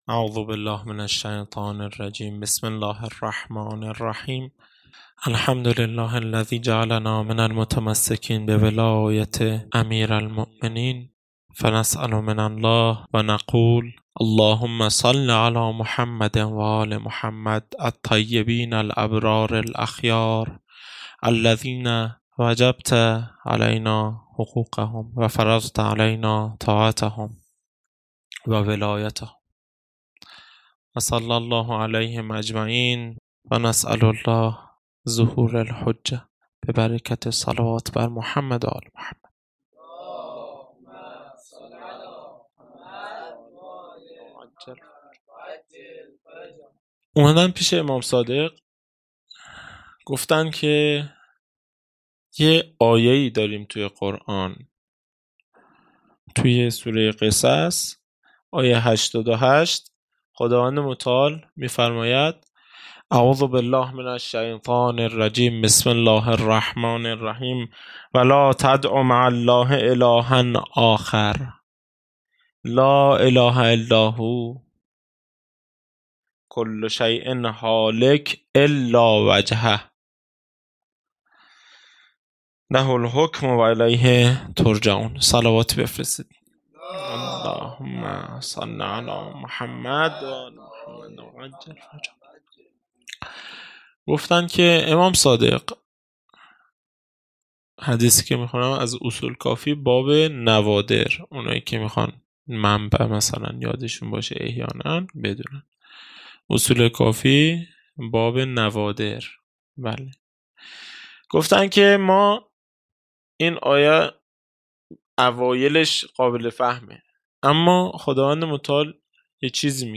خیمه گاه - هیئت بچه های فاطمه (س) - سخنرانی | سپاهی به فرماندهی عباس(ع)
جلسه هفتگی ( به مناسبت ولادت سرداران کربلا )